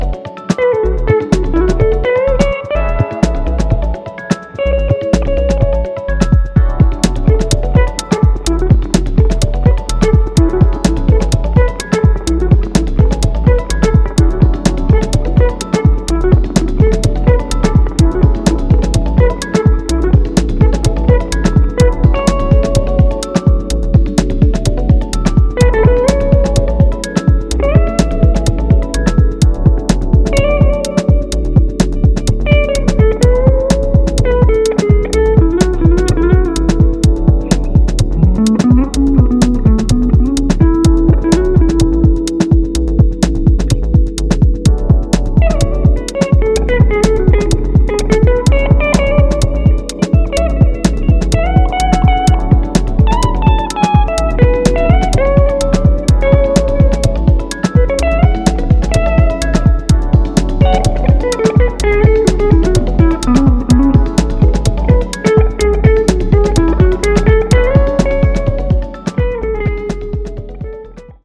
ブロークンなリズムに自身の演奏によるリードギターが淡く溶ける